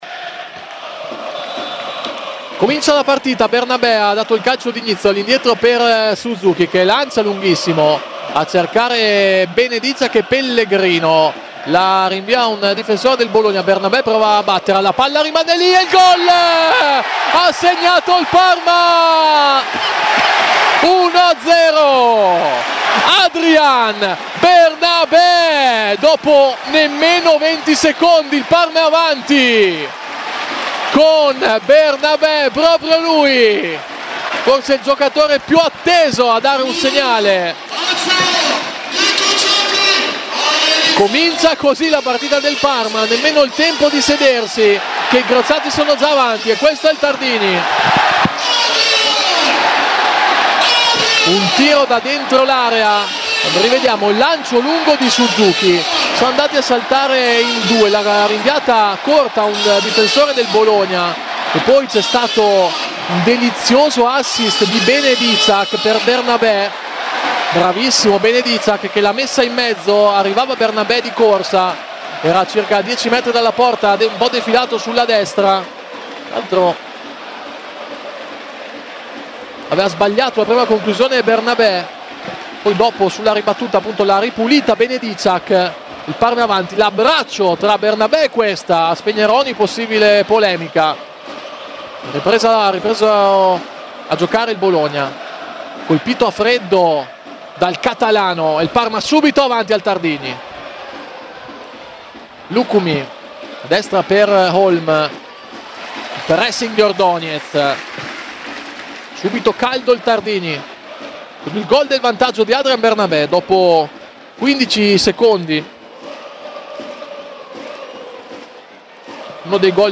Radiocronache Parma Calcio Parma - Bologna 1° tempo - 2 novembre 2025 Nov 02 2025 | 00:49:22 1x 00:00 / 00:49:22 Subscribe Share RSS Feed Share Link Embed